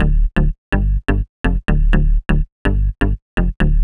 cch_bass_jacker_125_F.wav